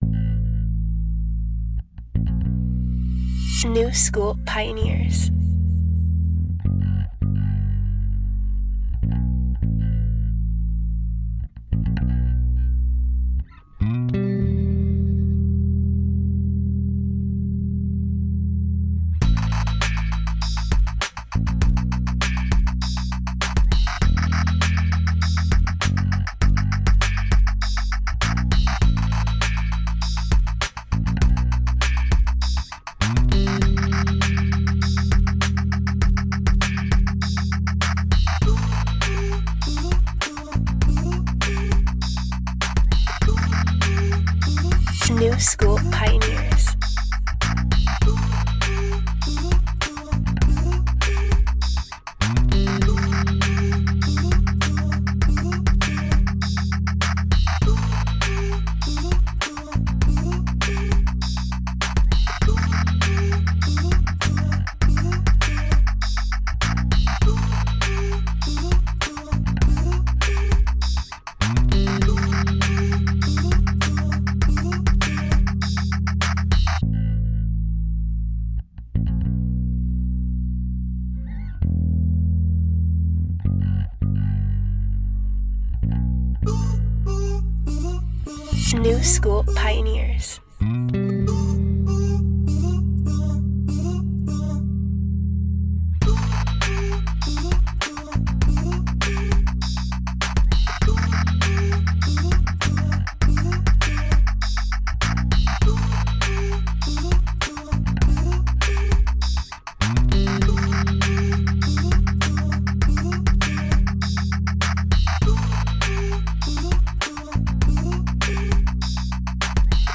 113bpm